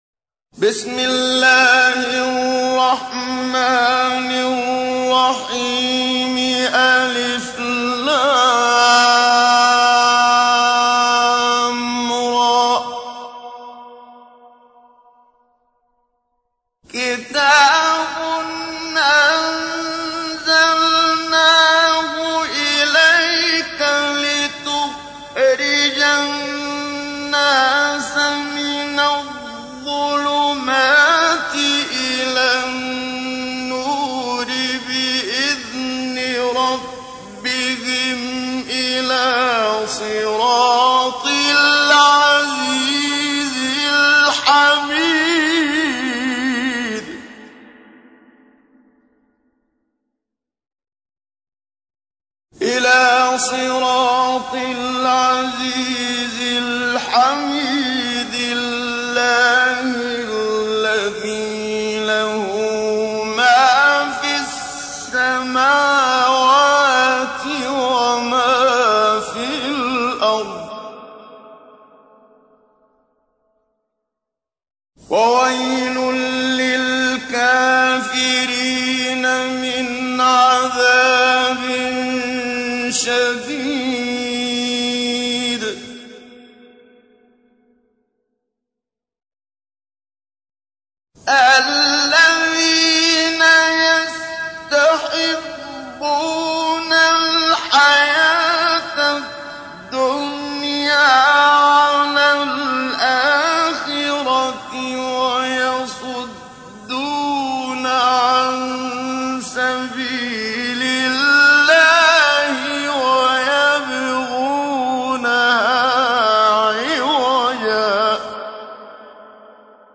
تحميل : 14. سورة إبراهيم / القارئ محمد صديق المنشاوي / القرآن الكريم / موقع يا حسين